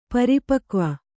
♪ pari pakva